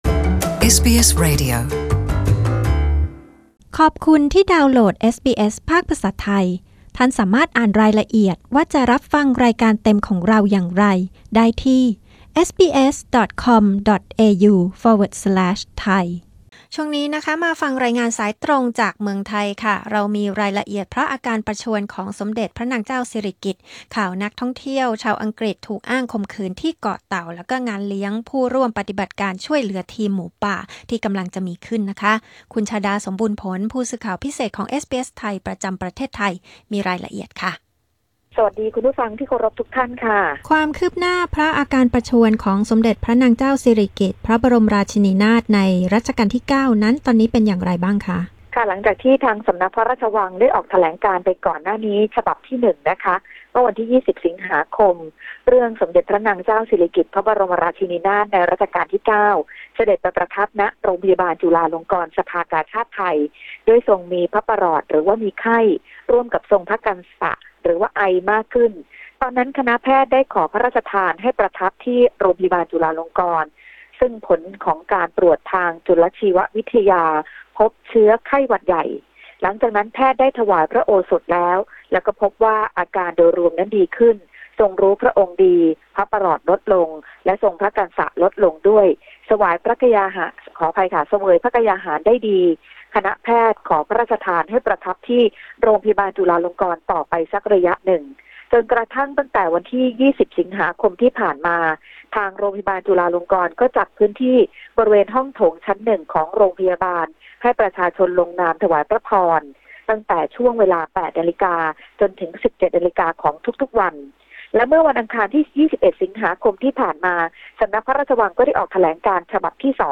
Thai phone-in news 30 AUG 2018